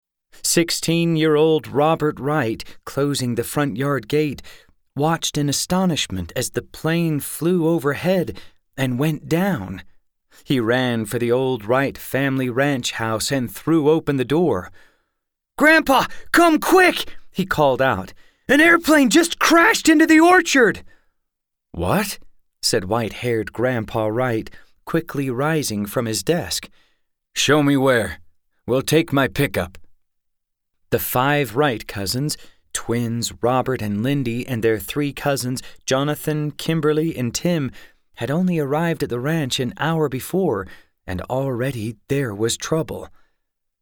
Fun Audiobooks!